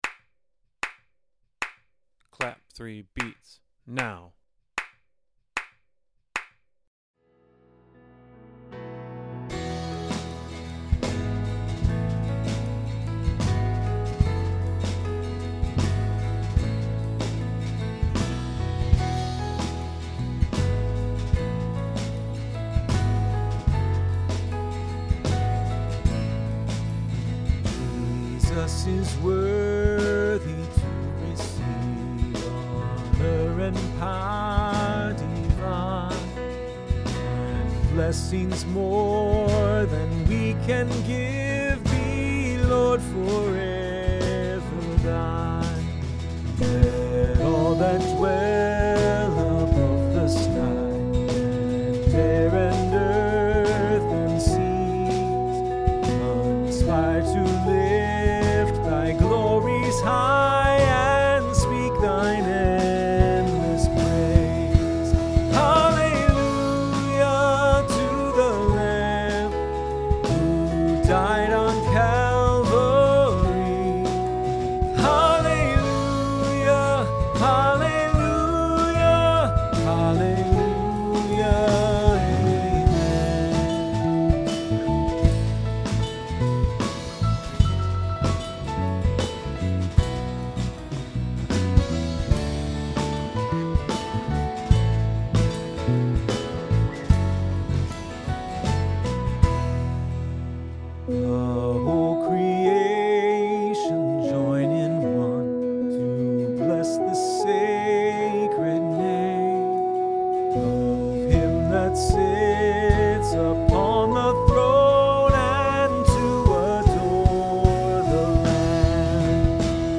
3. Backing Track -